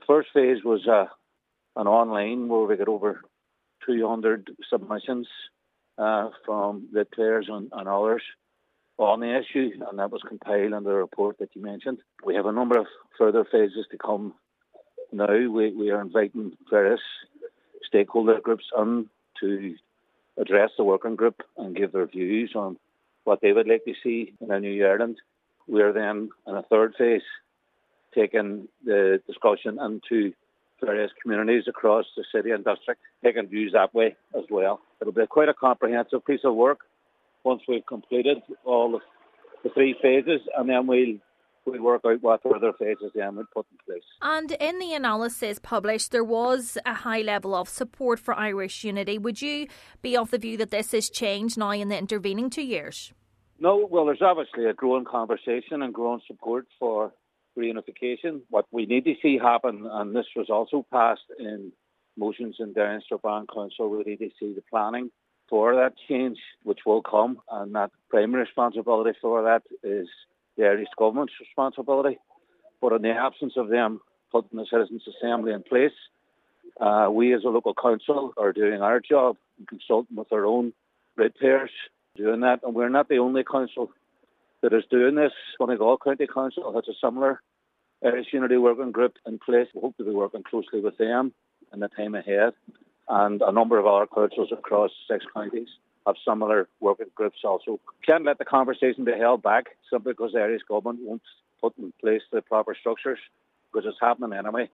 Councillor Conor Heaney hopes Irish Unity Working Groups on Councils on both sides of the border can collaborate in moving the conversation along: